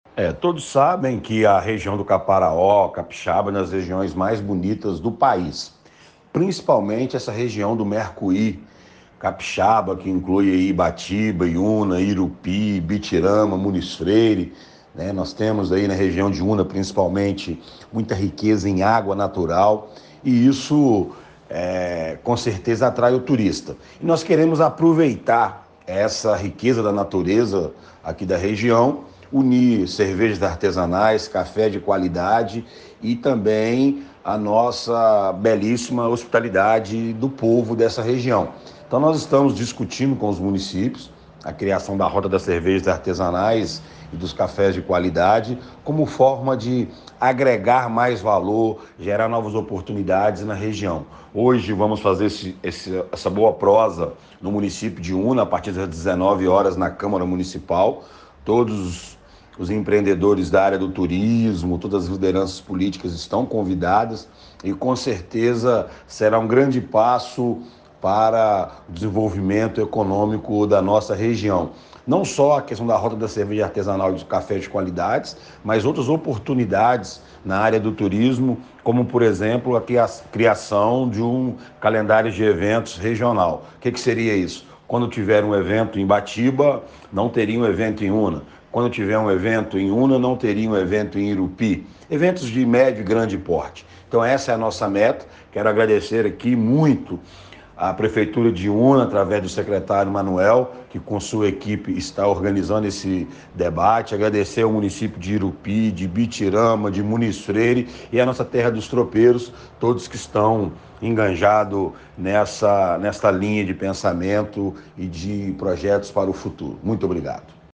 O prefeito Luciano Pingo, em conversa com nossa reportagem, destacou a beleza do Caparaó e falou da importância da integração entre os municípios com relação ao turismo.